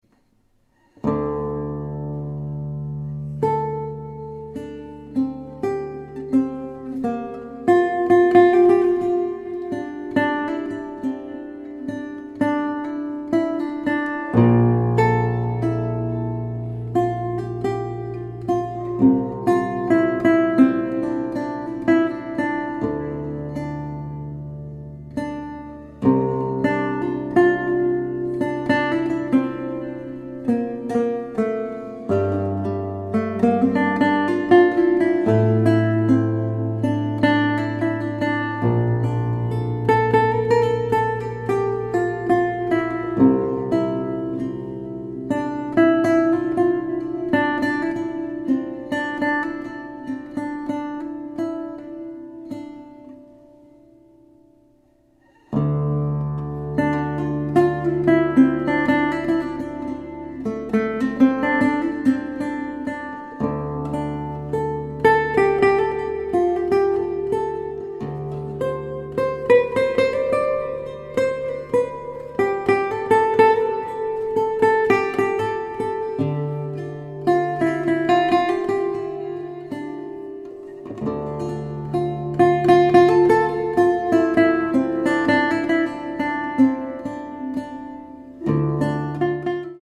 composer, lute & oud player from Japan
Lute , Relaxing / Meditative